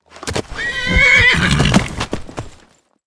(47.43 KB, prrrrt.mp3)